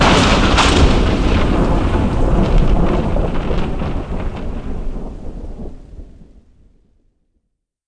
Thunder05.mp3